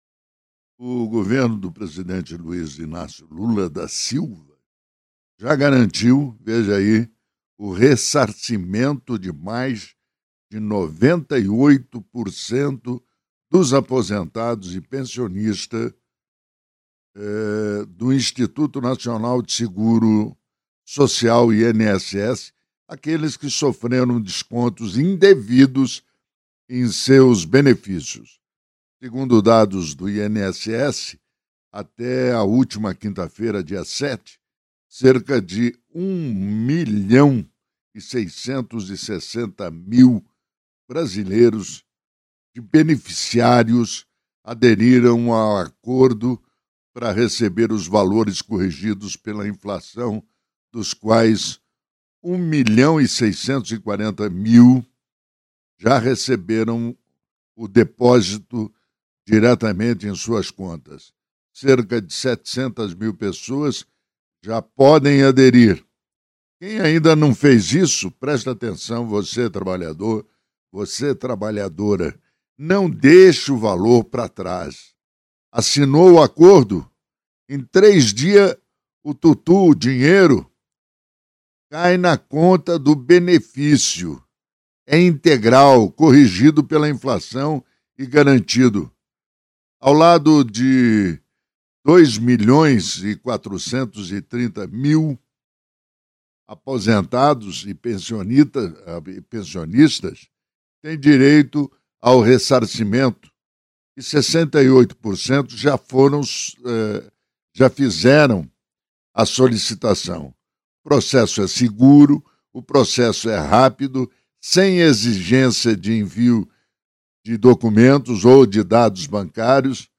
Comentário do jornalista